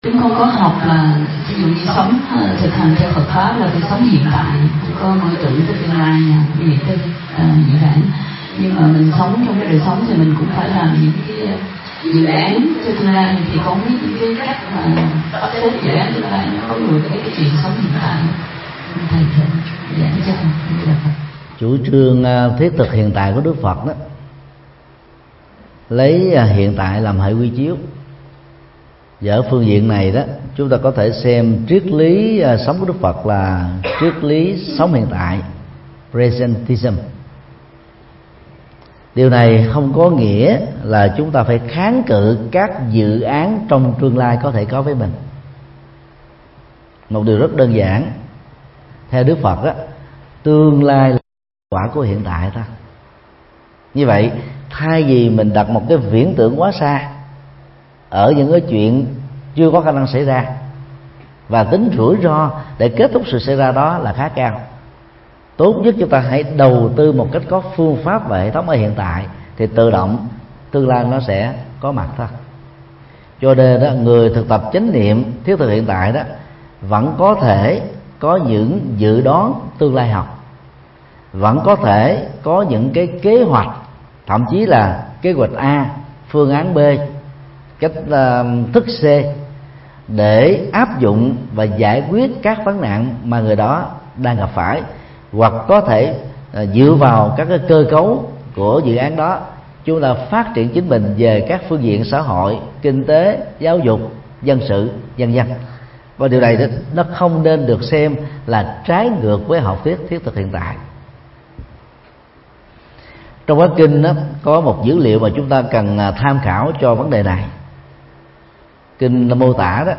Vấn đáp: Sống trong hiện tại
Giảng tai chùa Linh Phong,Thụy Sĩ,ngày 4 tháng 7 năm 2015